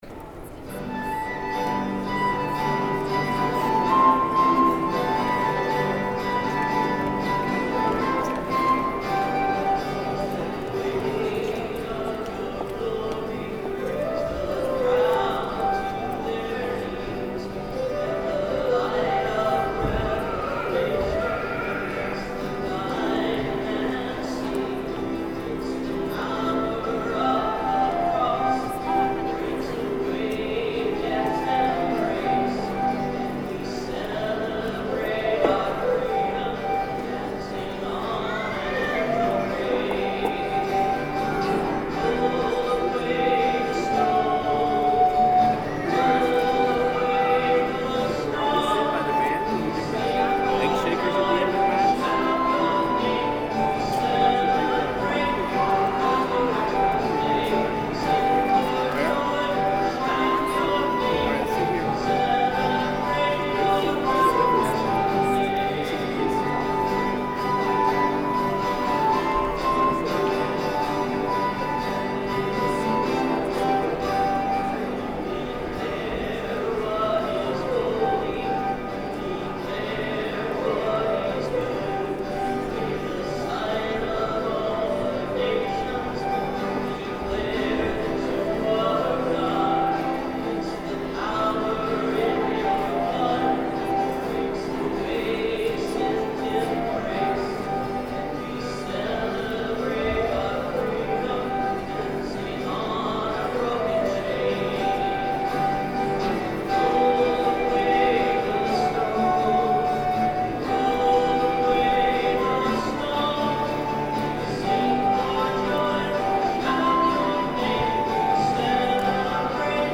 Music from the 10:30 Mass Easter Sunday, March 31, 2013:
(Note: My mic is pretty low.  Guitar volume isn't that great either. Flute volume is good though.)